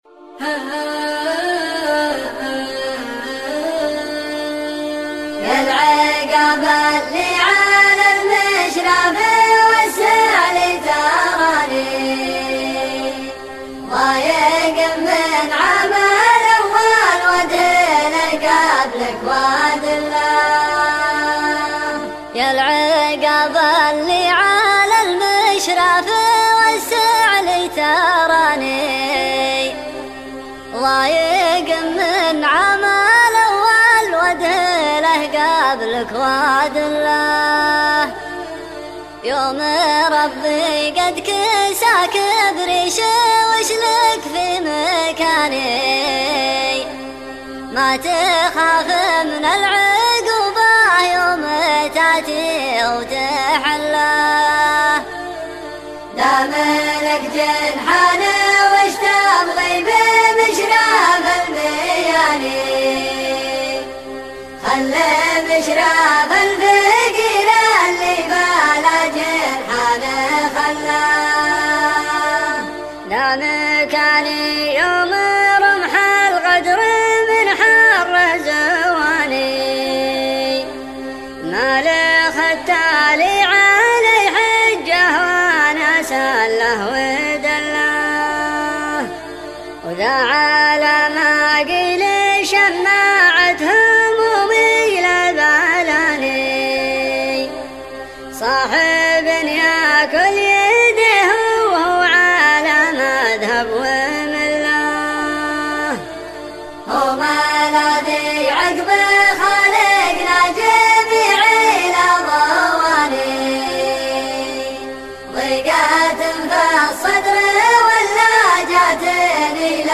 دويتو